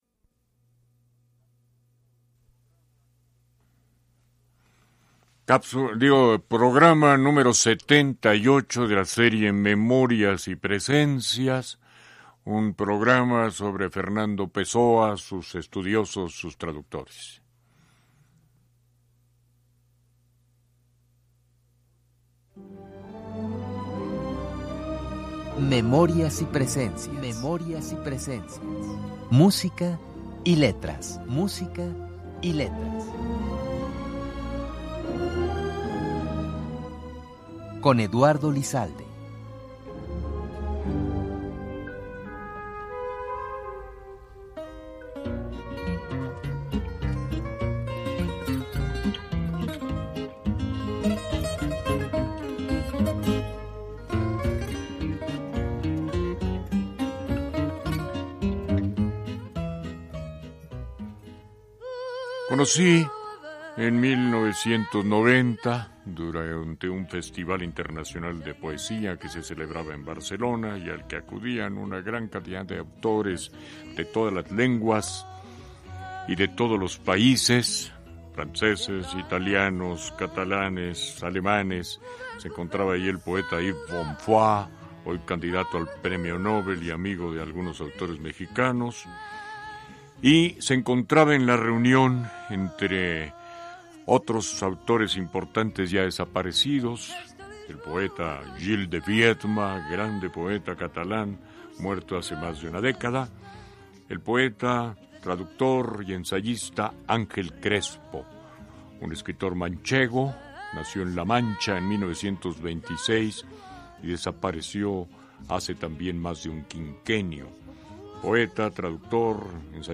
Escucha una semblanza sobre la vida y obra de Pessoa en el programa de Eduardo Lizalde “Memorias y presencias”, transmitido en noviembre de 2001.